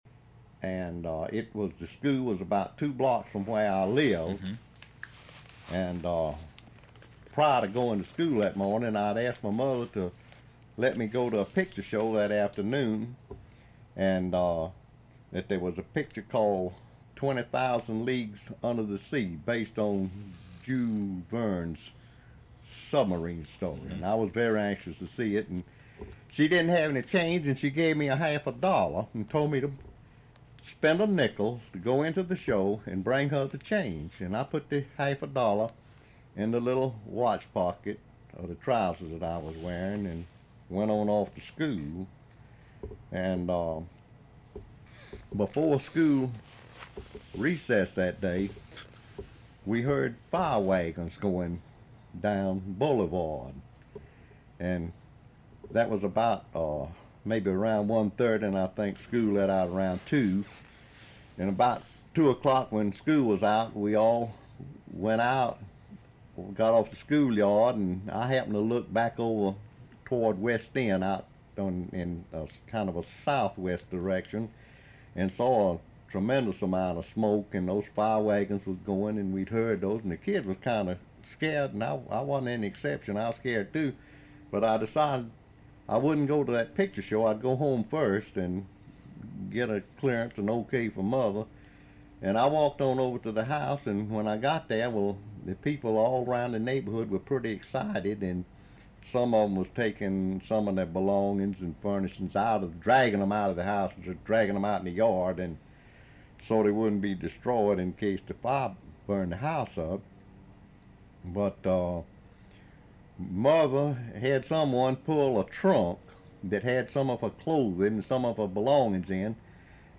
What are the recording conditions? Radio Free Georgia has even partnered with Atlanta History Center to record a fascinating series of oral histories that detail the experiences of Atlantans great and small between the first and second World Wars.